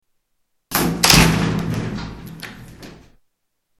Door closes with echo